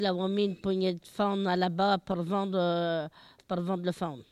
Localisation Barbâtre
Catégorie Locution